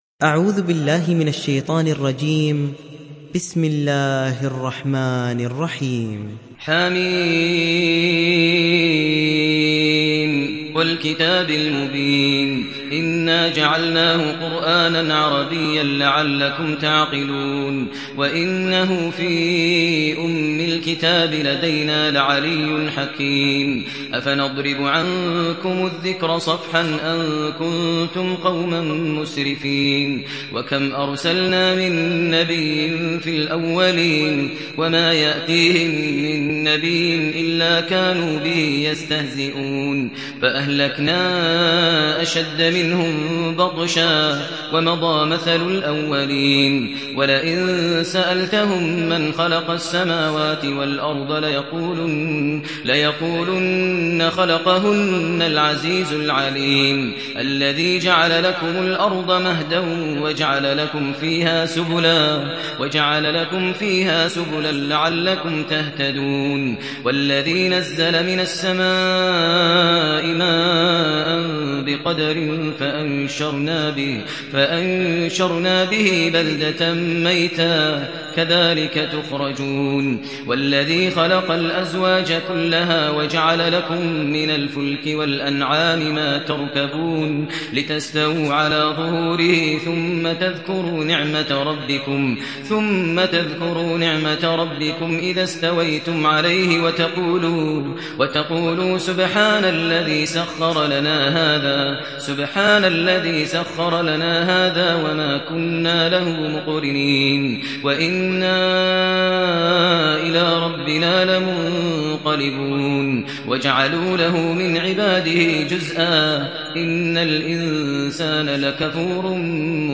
Sourate Az Zukhruf Télécharger mp3 Maher Al Muaiqly Riwayat Hafs an Assim, Téléchargez le Coran et écoutez les liens directs complets mp3